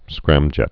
(skrămjĕt)